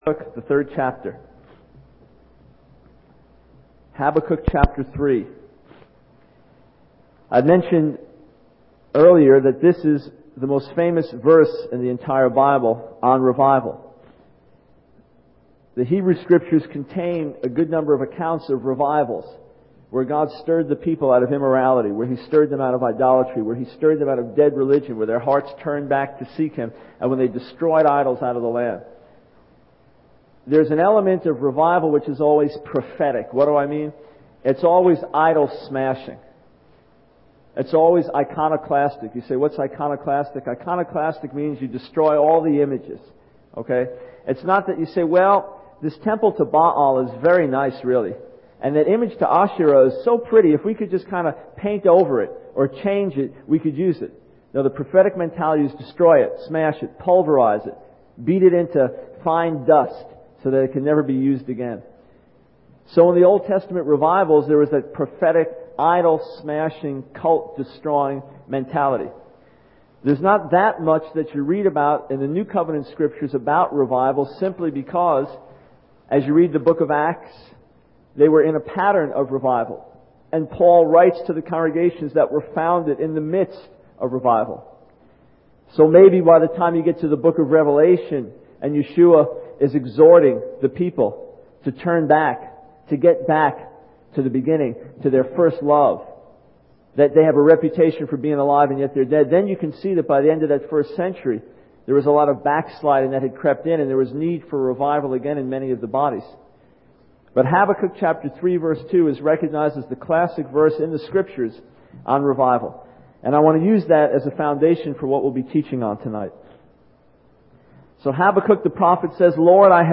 In this sermon, the preacher discusses the need for revival when the acts of God become only a memory. He refers to the book of Judges, specifically chapter six, to illustrate his point.